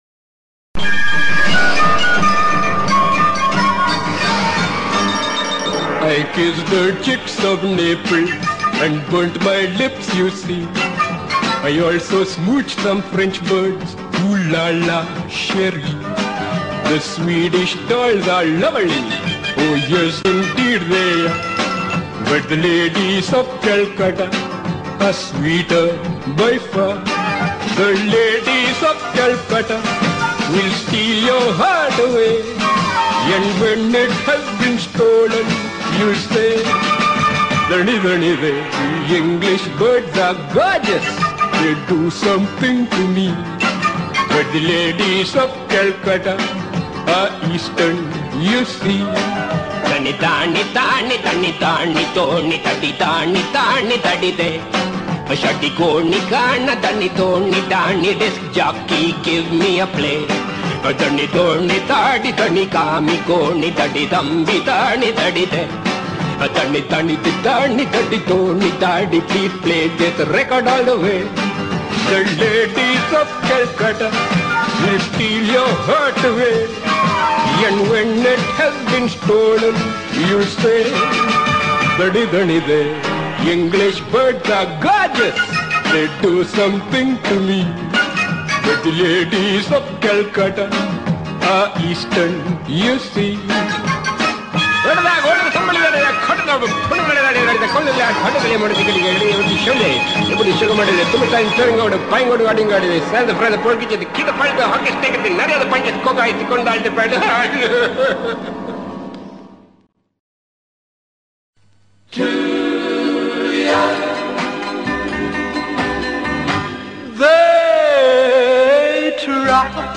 He decided to record his own “baila” styled